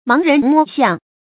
成語拼音máng rén mō xiàng
發音讀音
盲人摸象發音
成語正音摸，不能讀作“mē”。